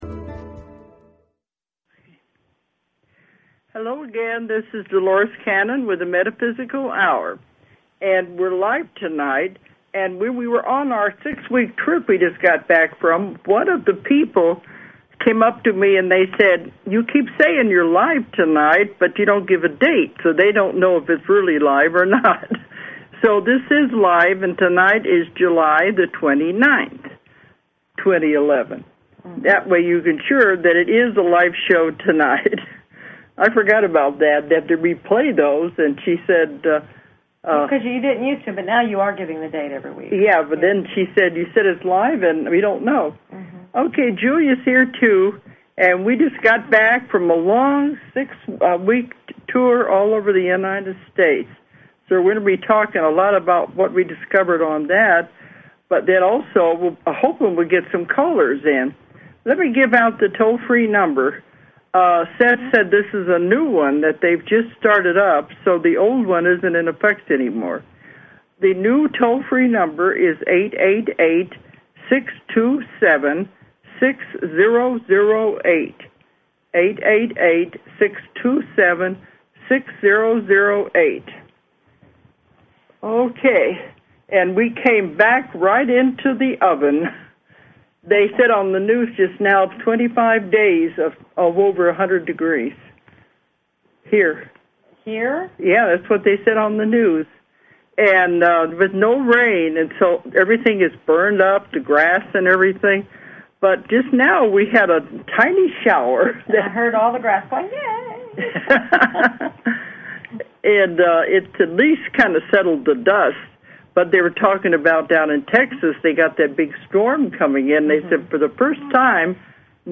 Talk Show Episode, Audio Podcast, The_Metaphysical_Hour and Courtesy of BBS Radio on , show guests , about , categorized as